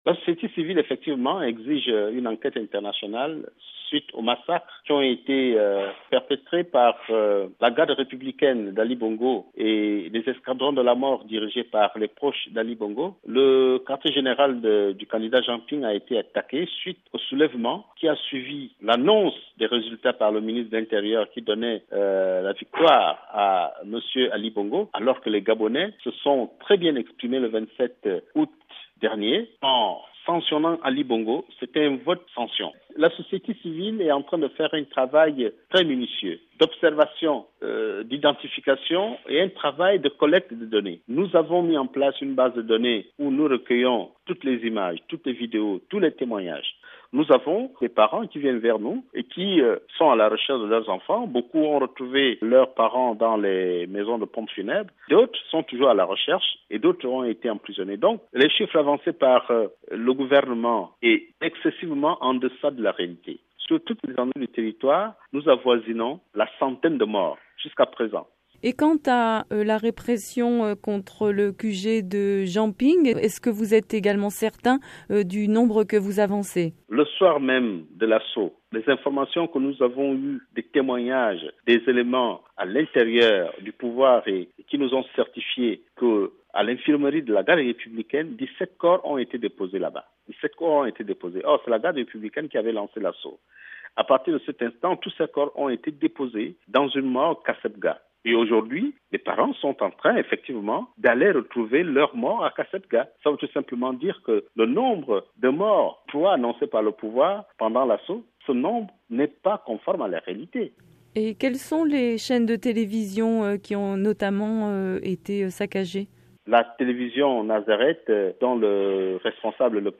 joint à Libreville